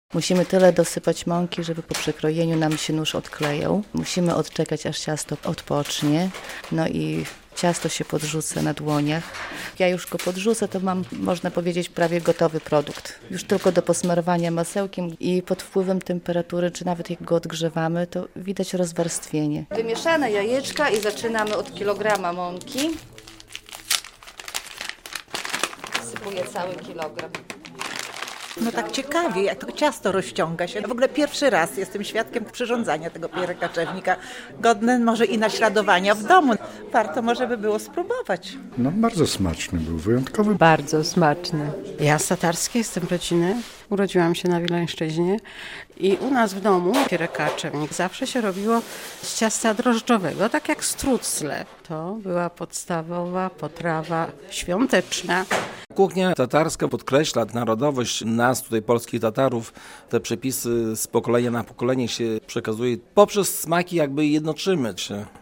To jedno z najbardziej rozpoznawalnych dań kuchni tatarskiej. Jak przyrządzić pierekaczewnik - można było zobaczyć podczas warsztatów w Domu Kultury Muzułmańskiej w Białymstoku.